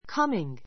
kʌ́miŋ